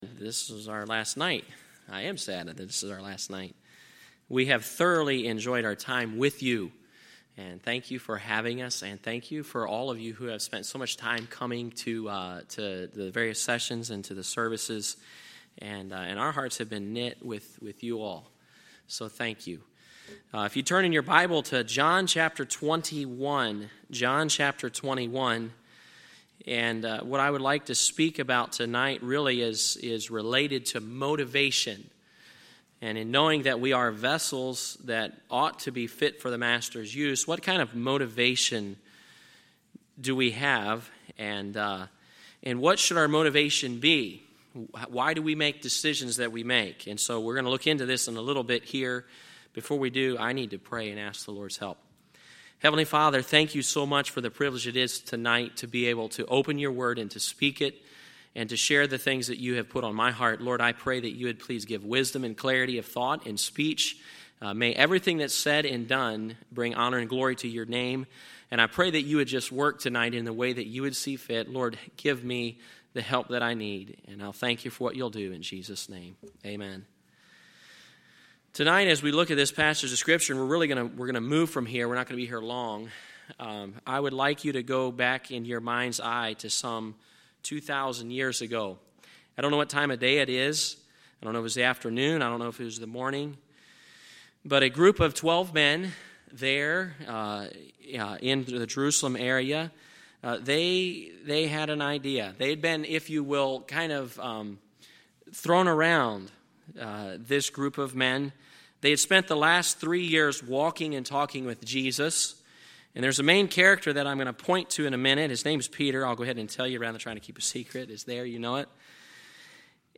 Tuesday, September 22, 2019 – Missions Conference Session 5